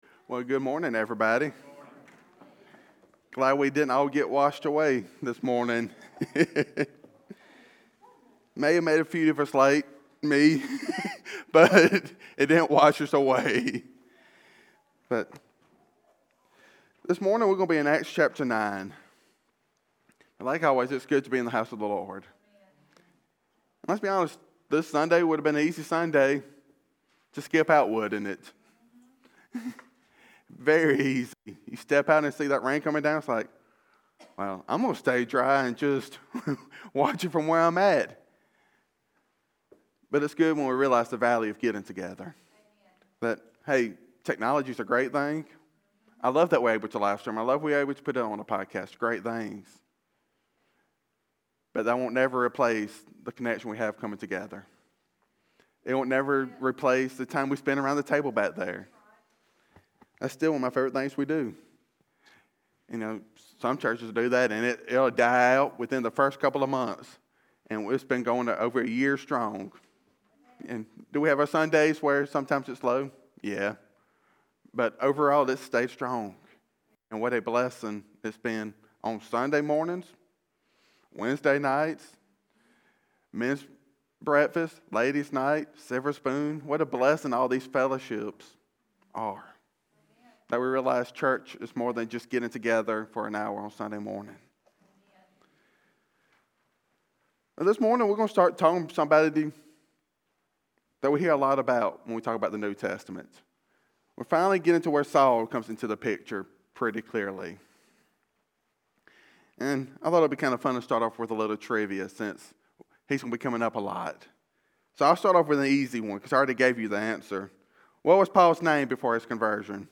In this sermon from Acts 9, we explore the dramatic transformation of Saul of Tarsus on the road to Damascus.